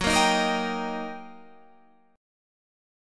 Eb/Gb chord